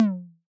Index of /musicradar/retro-drum-machine-samples/Drums Hits/Raw
RDM_Raw_SY1-Tom01.wav